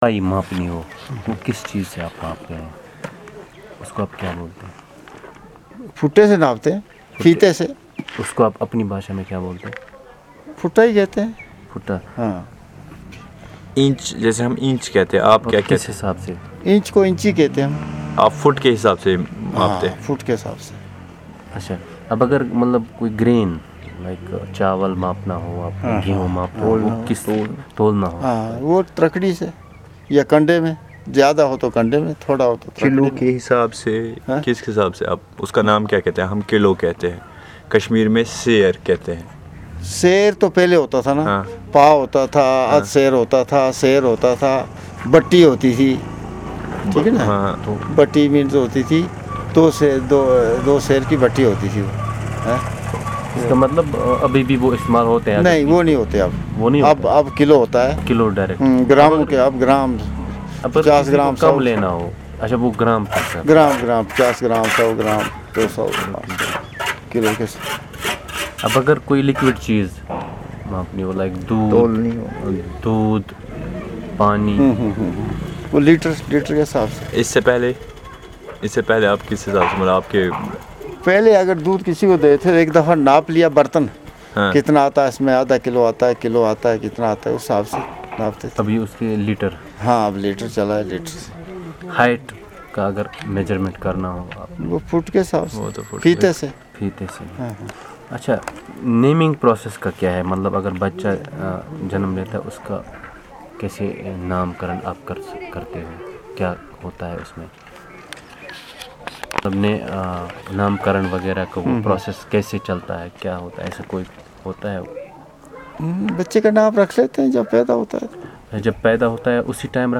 Conversation about the life cycle and the measurement system of the community